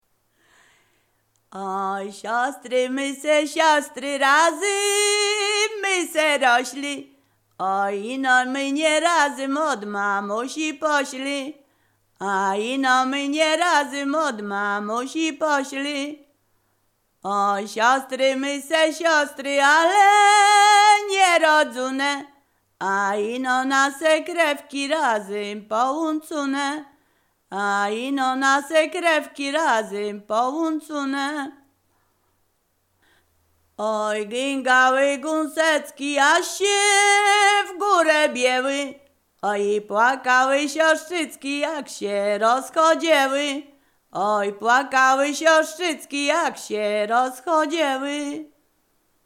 Ziemia Radomska
Przyśpiewki
miłosne weselne wesele przyśpiewki